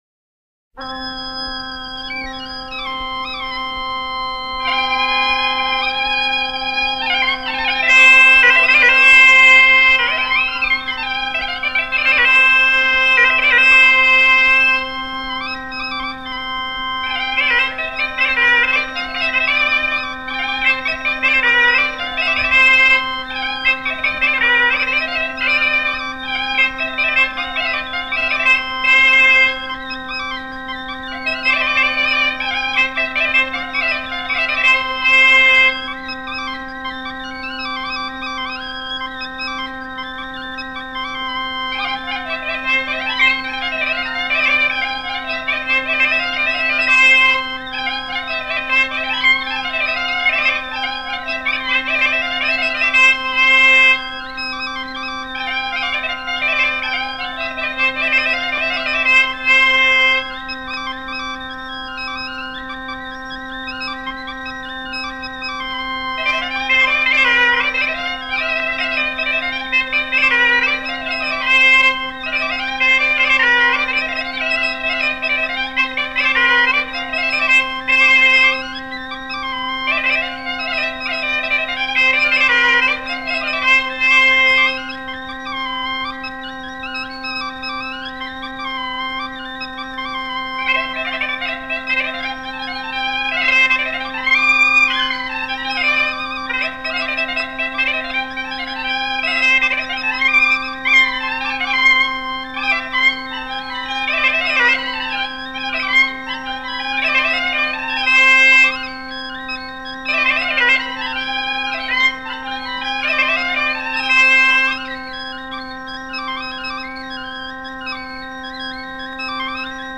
Suite de gavottes enregistrées en 1931 par le couple de sonneurs biniou-bombarde
danse : gavotte bretonne